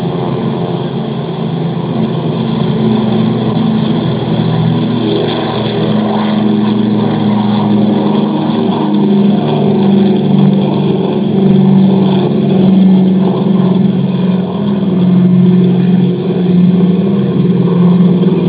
DC-3 Sound Files
1.wav Number 2 (left) engine start up (number 1 engine running) after two flights were made during same day (outside recording).